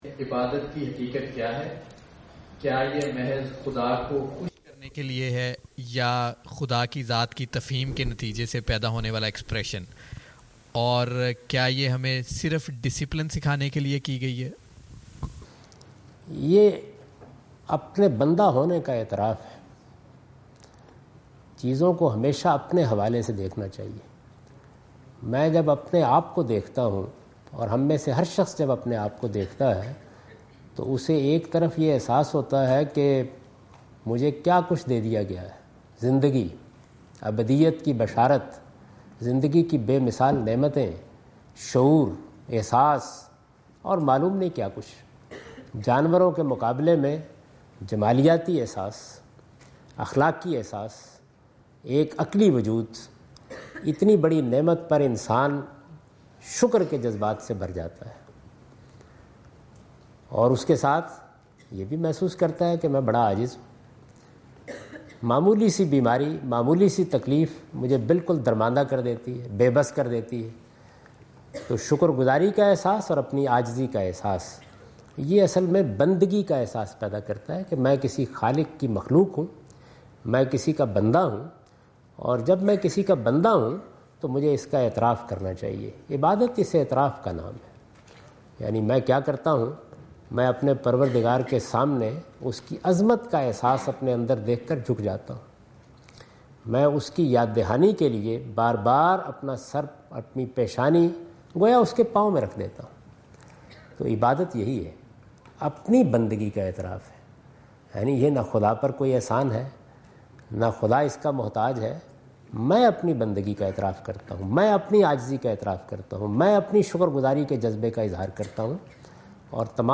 Javed Ahmad Ghamidi answer the question about "reality of worship" in Macquarie Theatre, Macquarie University, Sydney Australia on 04th October 2015.
جاوید احمد غامدی اپنے دورہ آسٹریلیا کے دوران سڈنی میں میکوری یونیورسٹی میں "عبادت کی حقیقت" سے متعلق ایک سوال کا جواب دے رہے ہیں۔